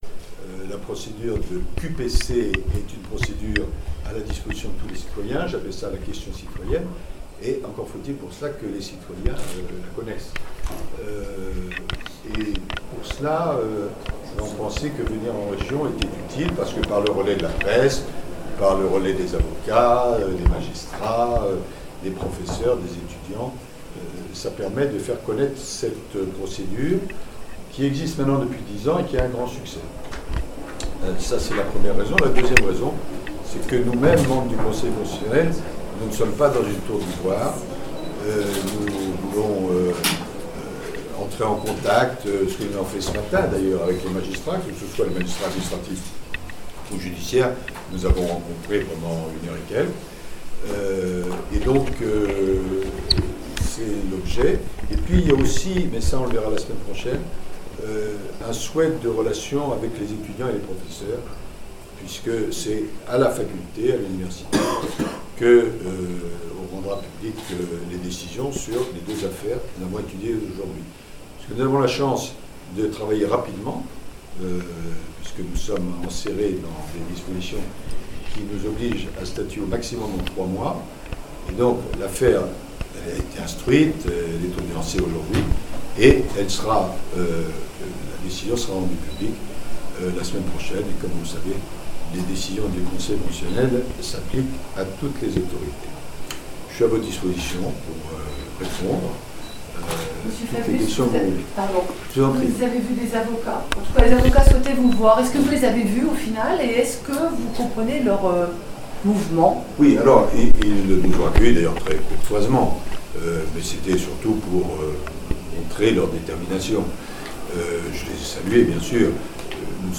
Conférence de presse Laurent Fabius, Président du Conseil Constitutionnel
Conférence-presse-Laurent-Fabius.mp3